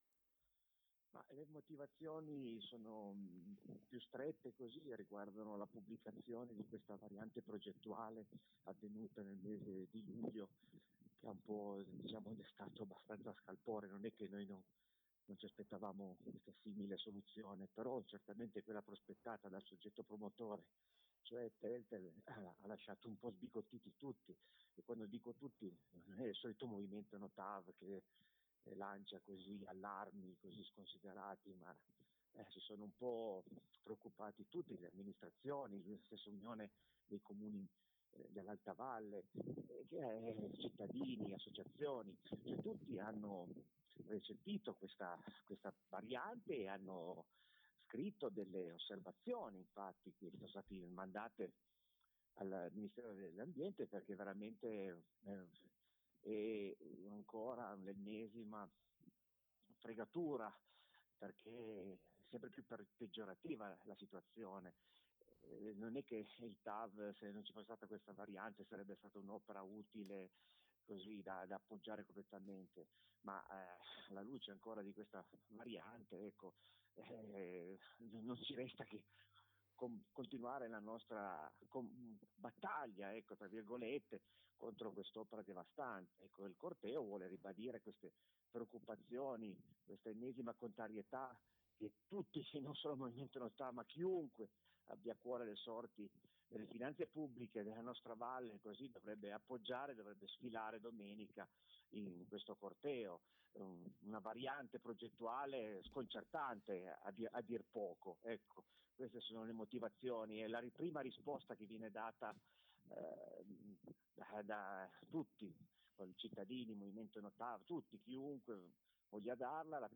[audio:2017-09-21-ancora-chiomonte.mp3]